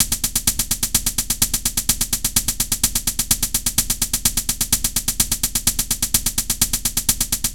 Hats Loop_.wav